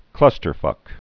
(clŭstər-fŭk)